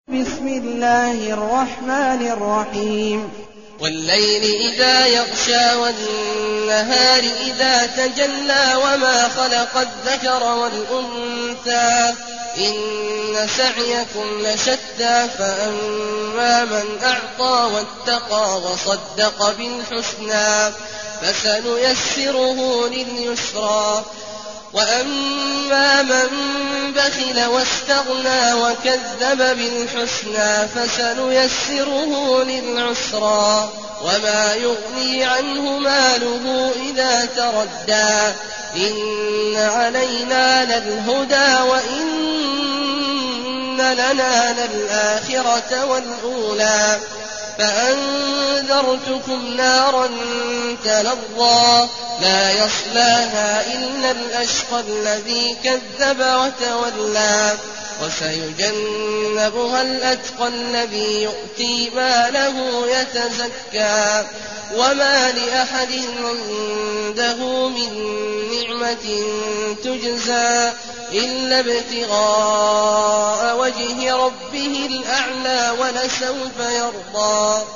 المكان: المسجد النبوي الشيخ: فضيلة الشيخ عبدالله الجهني فضيلة الشيخ عبدالله الجهني الليل